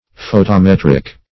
Photometric \Pho`to*met"ric\, Photometrical \Pho`to*met"ric*al\,